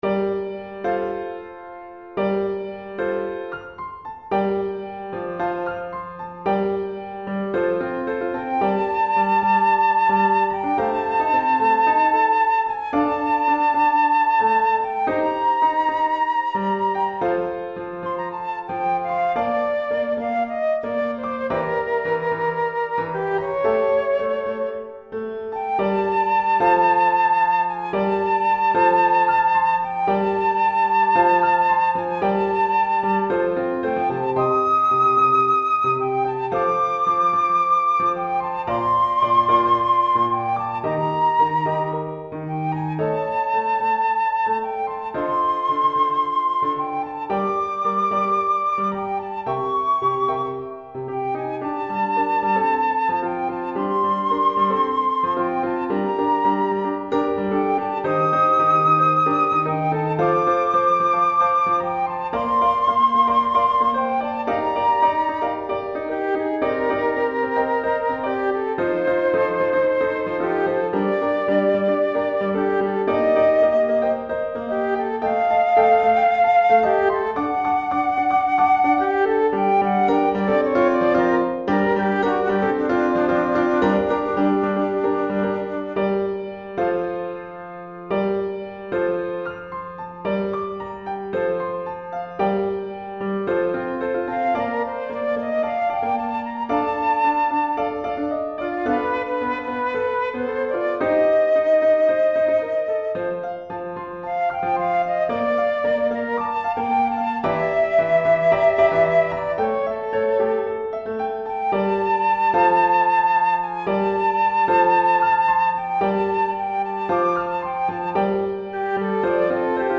Flûte Traversière et Piano